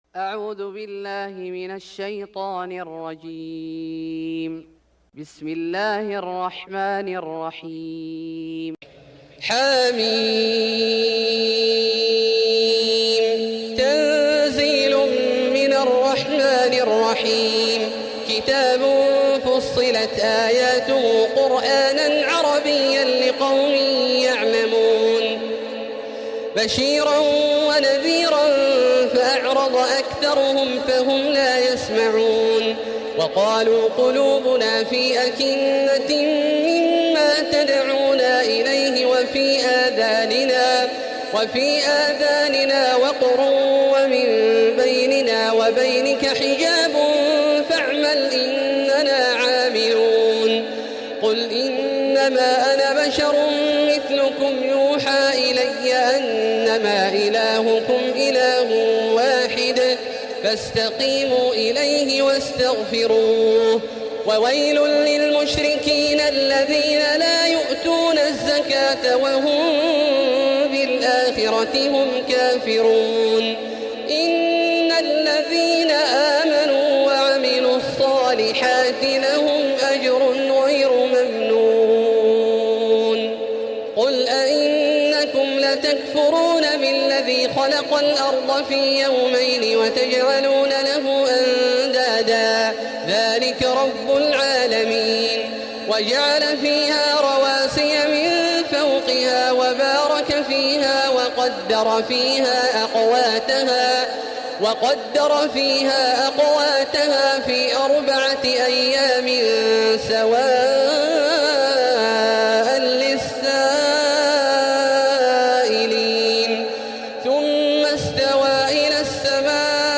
سورة فصلت Surat Fussilat > مصحف الشيخ عبدالله الجهني من الحرم المكي > المصحف - تلاوات الحرمين